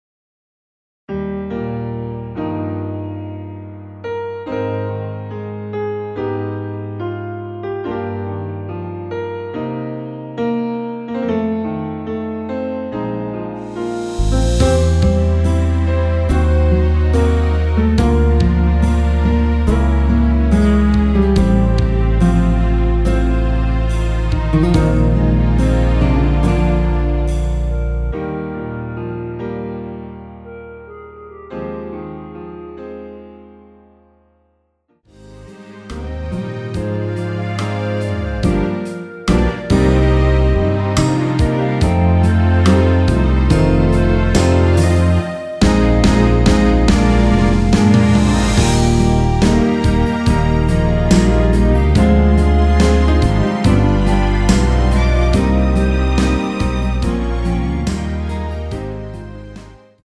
원키에서(-2)내린 멜로디 포함된 MR입니다.(미리듣기 확인)
Eb
앞부분30초, 뒷부분30초씩 편집해서 올려 드리고 있습니다.
중간에 음이 끈어지고 다시 나오는 이유는